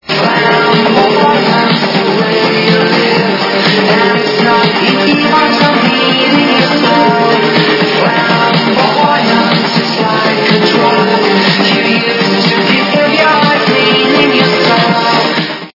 западная эстрада
качество понижено и присутствуют гудки